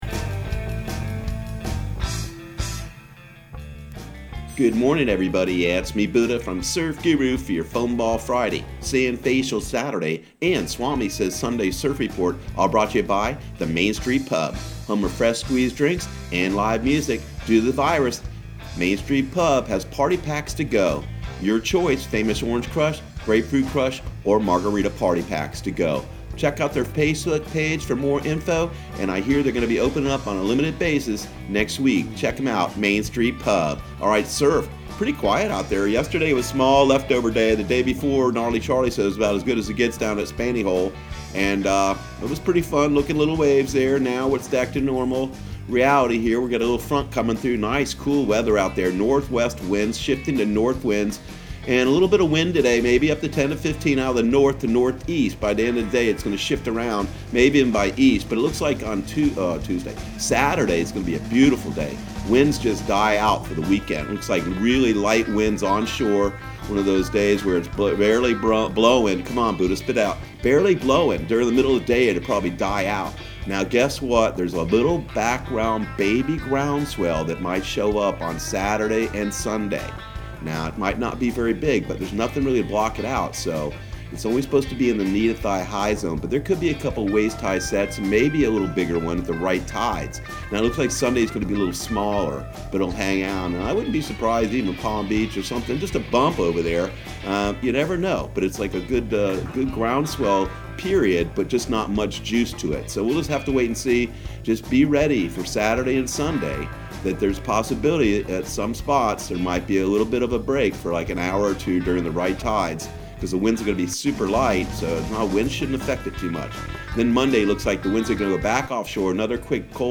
Surf Guru Surf Report and Forecast 05/01/2020 Audio surf report and surf forecast on May 01 for Central Florida and the Southeast.